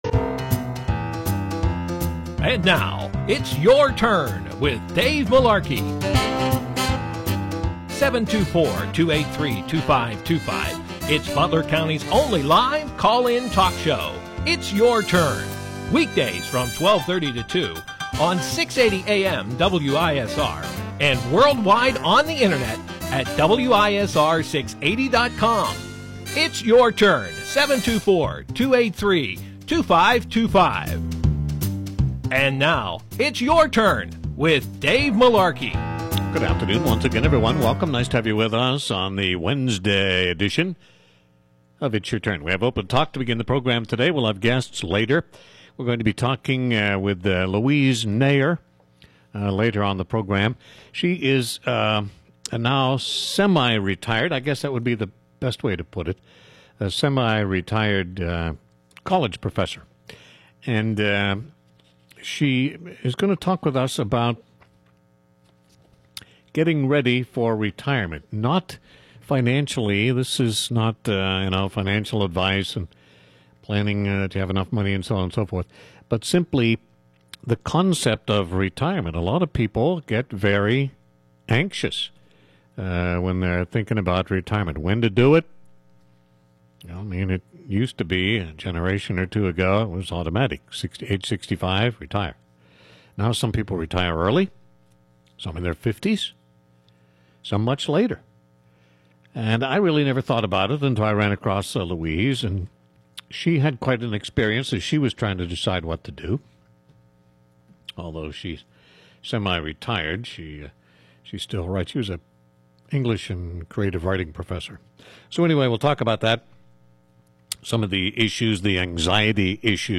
OPEN TALK on the Wednesday June 14 It’s Your Turn show.